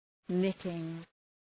Προφορά
{‘nıtıŋ}